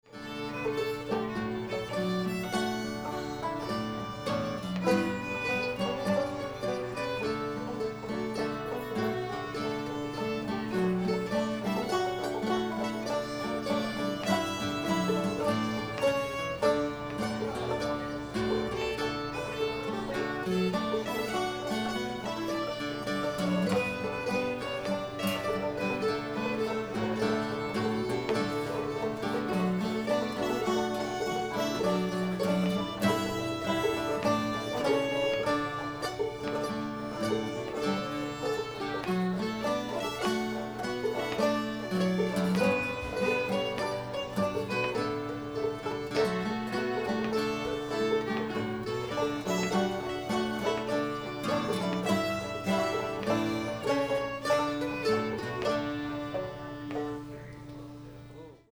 battle hymn of the republic [D]